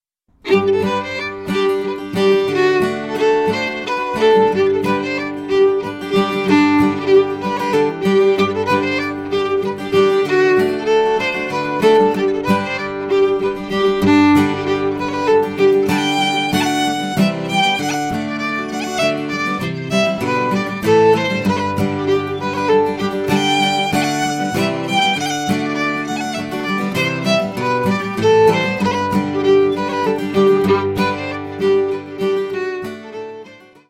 Voicing: Fiddle/CD